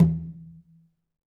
Conga-HitN_v3_rr1_Sum.wav